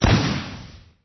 hit.mp3